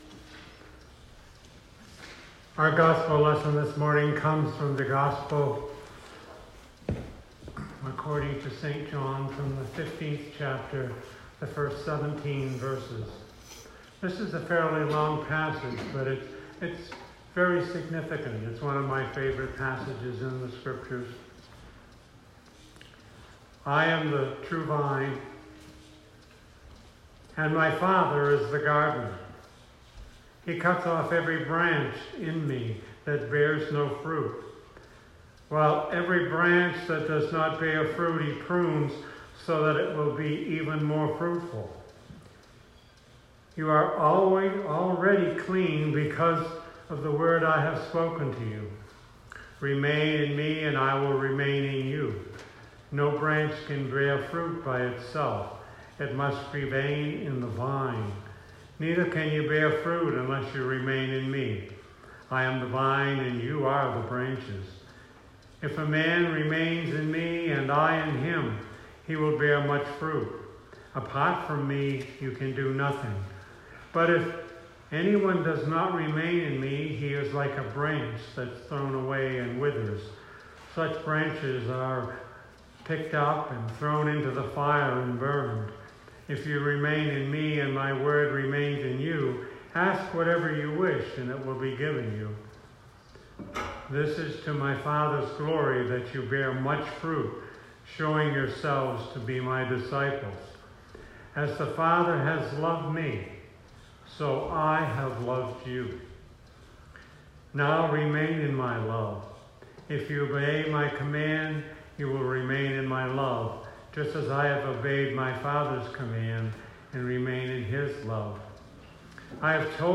Sermon 2019-09-08